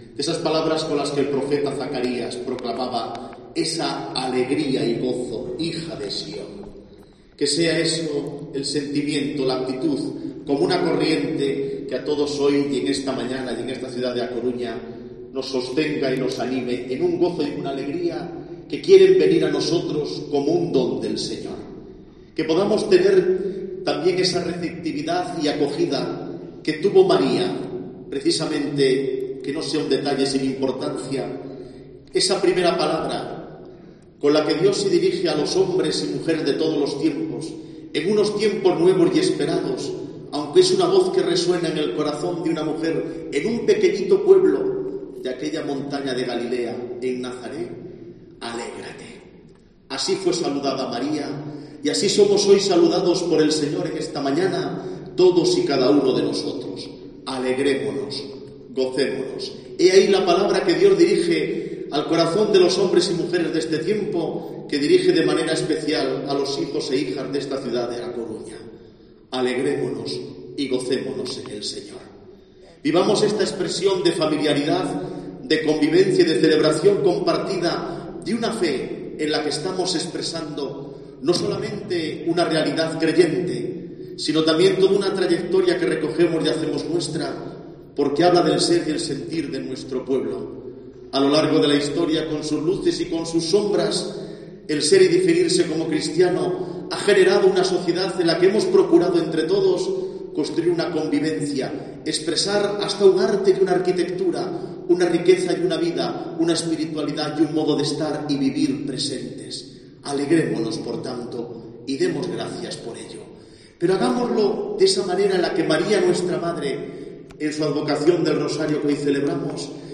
Homilía del arzobispo de Santiago, monseñor Francisco José Prieto